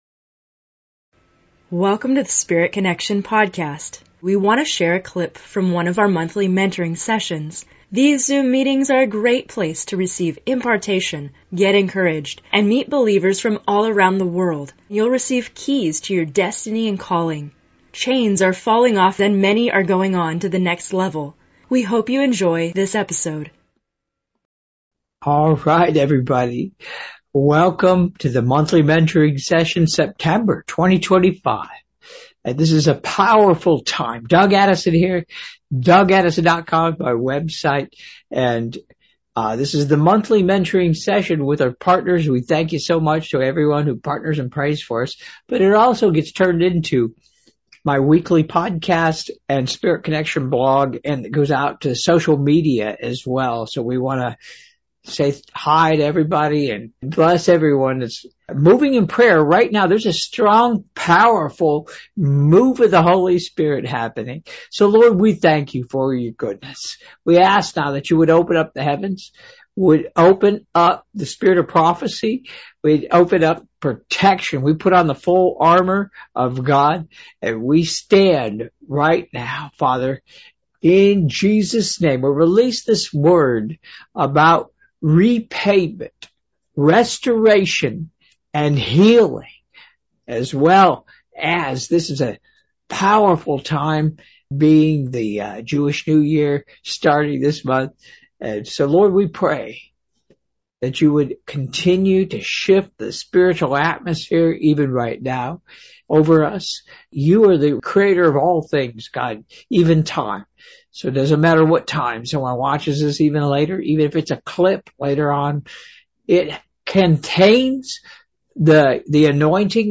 His powerful, lighthearted style of teaching and coaching helps open people to discover their spiritual identity and personal destiny as they experience God’s supernatural love and power.